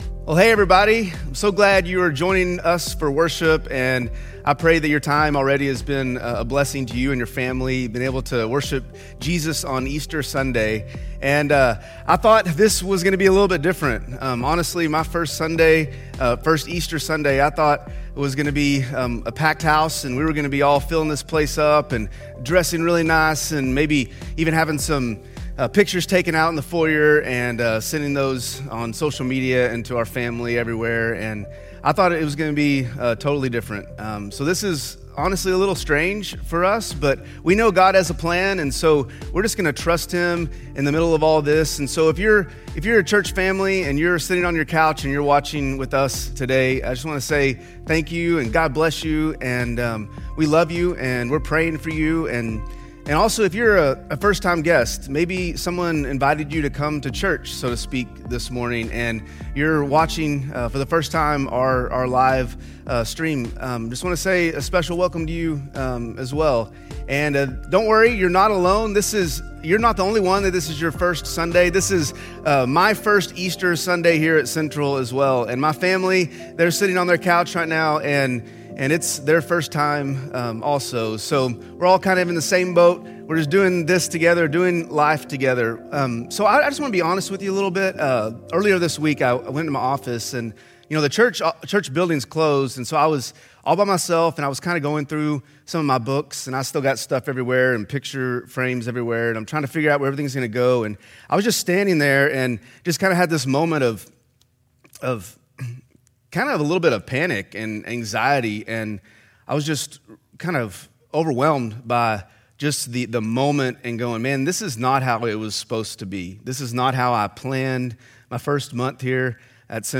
A message from the series "Asking for a Friend."
Our at home edition of Easter at Central.